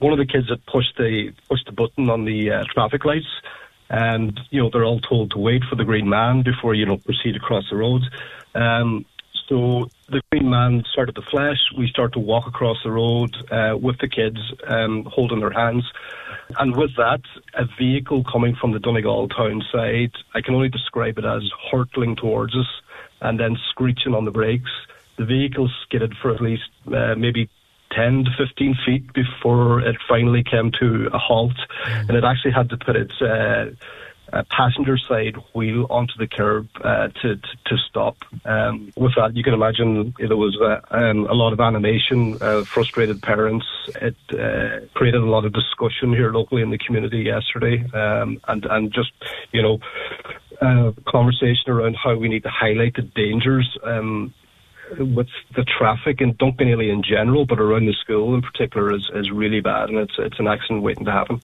on this morning’s Nine ’til Noon Show